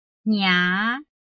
臺灣客語拼音學習網-客語聽讀拼-南四縣腔-開尾韻
拼音查詢：【南四縣腔】ngia ~請點選不同聲調拼音聽聽看!(例字漢字部分屬參考性質)